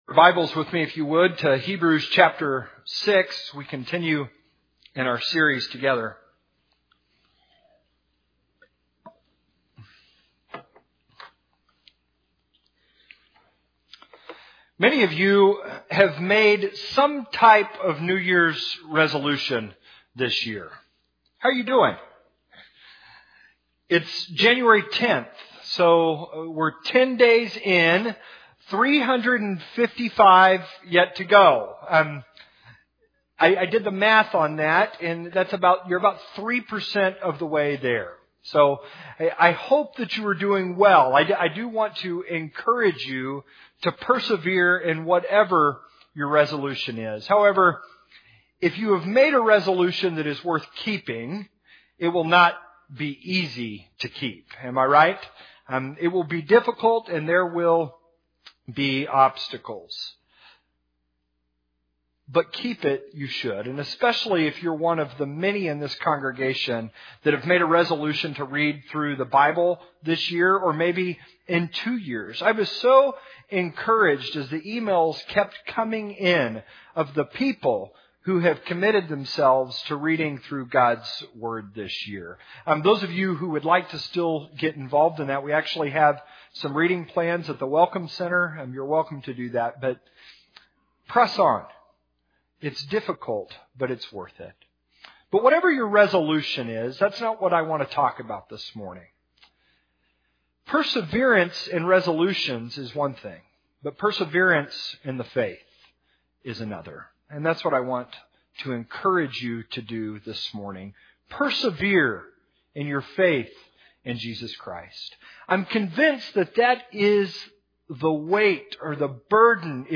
I encourage you to maintain your resolve to accomplish your goal, especially if you were one of many people in this congregation who resolved to read through the Bible this year.